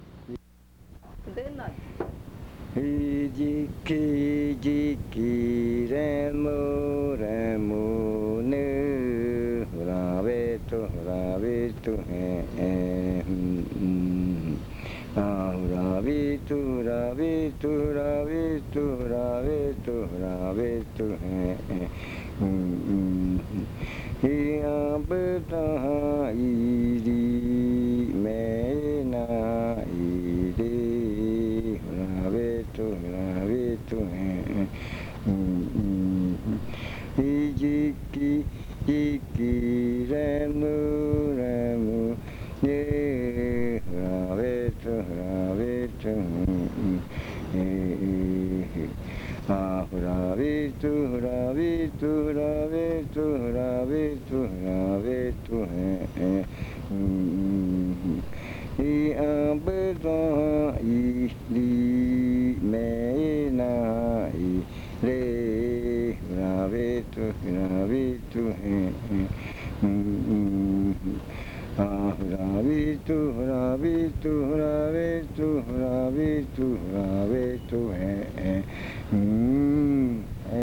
Leticia, Amazonas
Canción de madrugada. 5:00 AM.
Early morning chant. 5:00 AM.